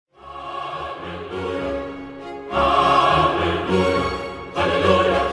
hallelujah_chorus_sound_effect-mp3cut.mp3